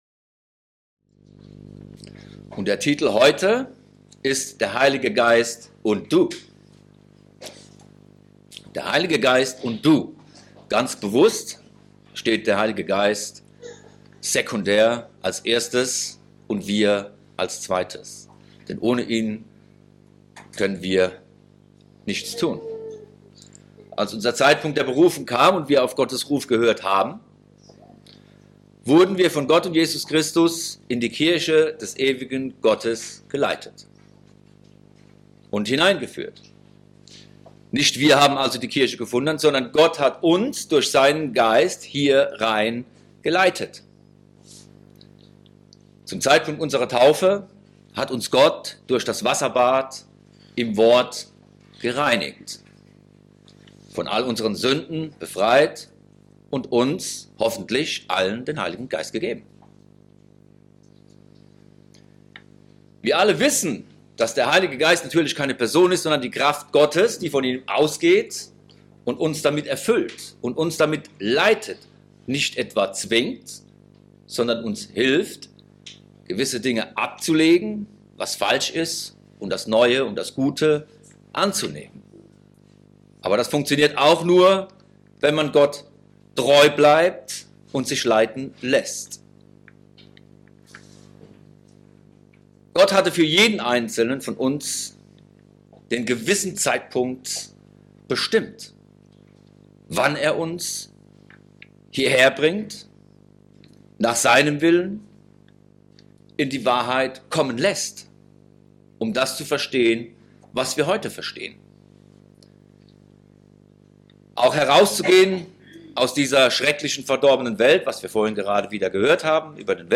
Wir erklären in dieser Predigt, was Gott von uns erwartet, was es mit dem Heiligen Geist zu tun hat, und was es bedeutet, im Leib Christi zu sein.